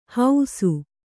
♪ hausu